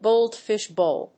アクセントgóldfish bówl